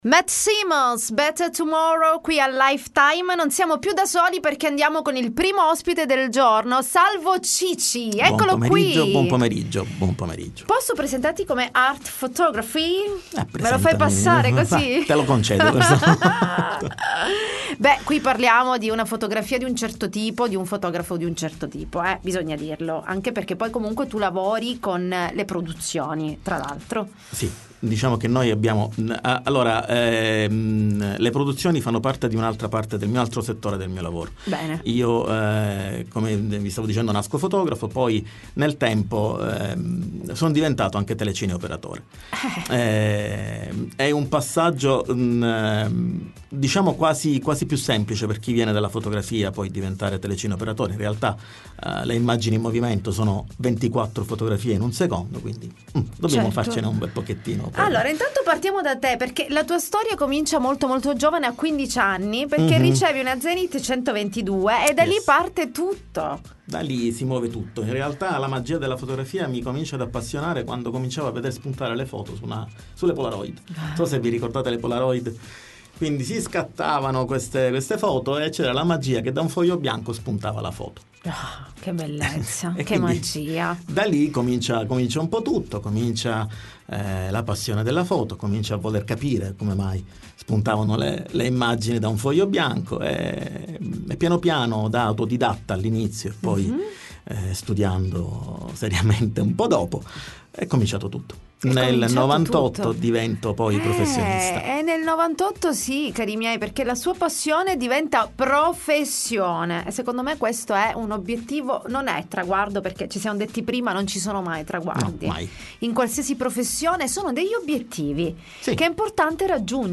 Life Time Intervista